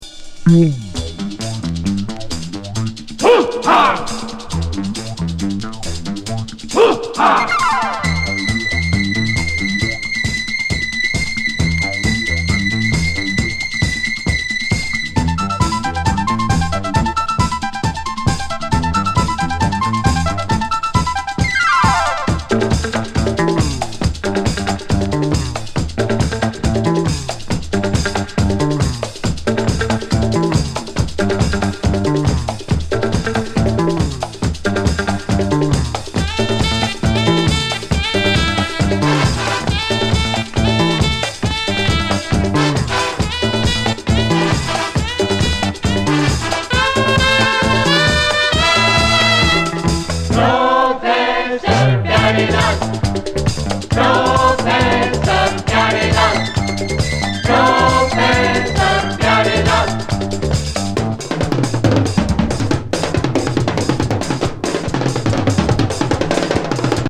疾走シンセ～ホーン・ファンカー